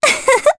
Mediana-Vox-Laugh_jp.wav